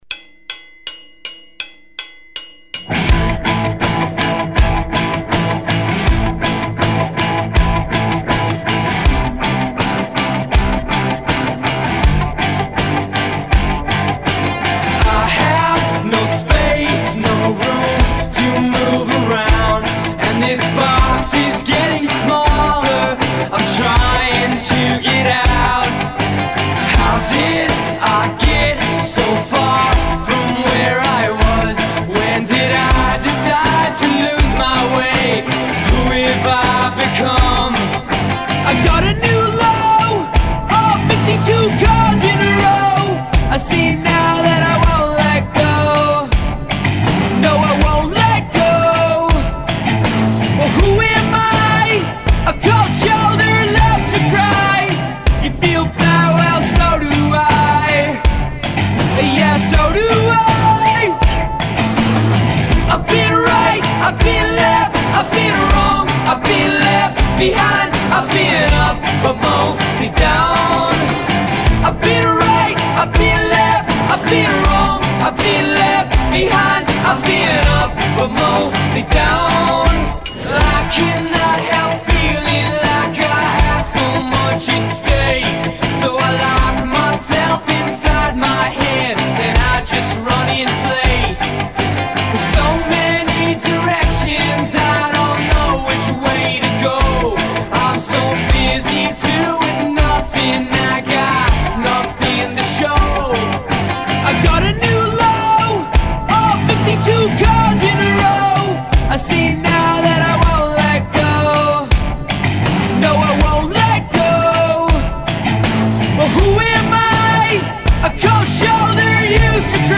A gritty, genuine, fuzzed out rock sound.
The style says desert and suburbs to me.